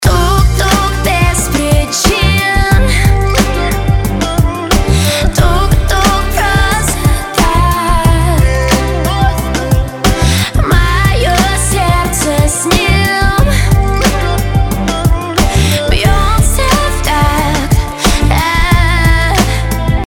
поп
женский вокал
романтичные